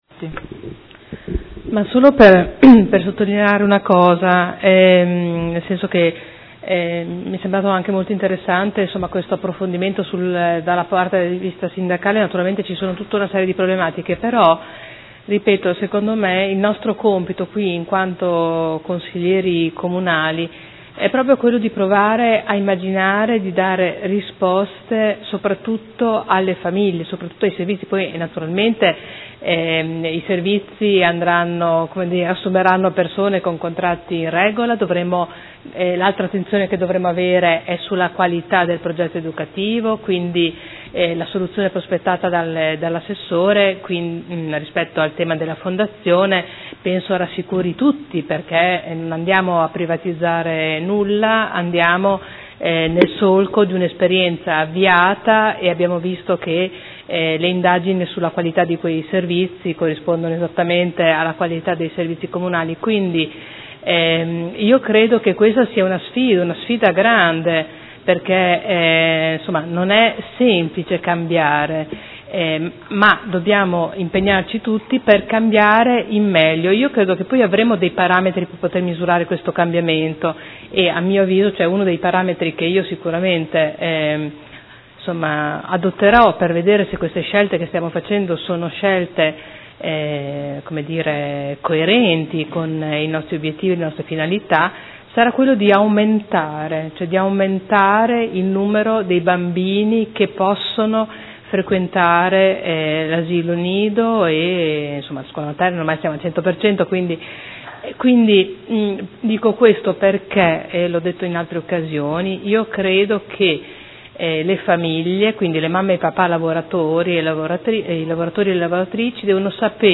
Seduta del 14/04/2015 Dibattito. Interrogazione del Consigliere Rocco (FaS-S.I.) avente per oggetto: Bando comunale servizio nidi e Interrogazione del Gruppo Consiliare Per Me Modena avente per oggetto: Qual è la situazione dei nidi a Modena?